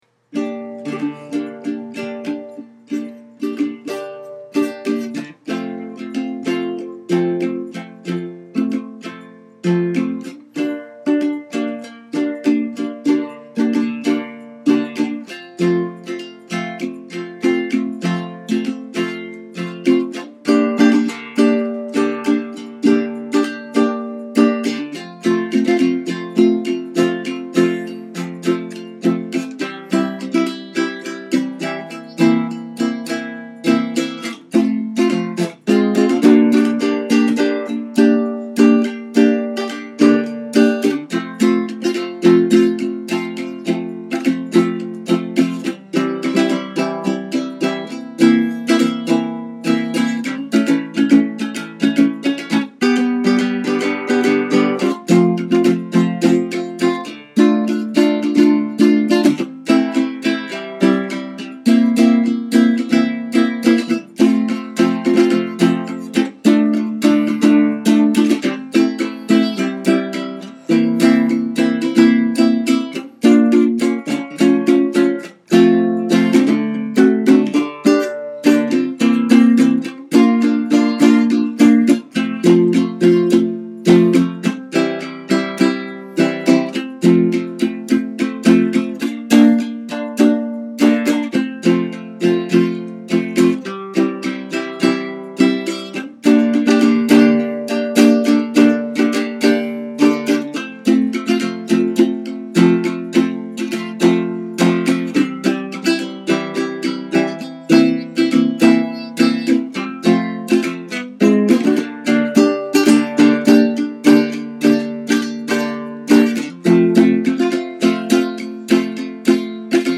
my second uke release; a cover of  his glam hit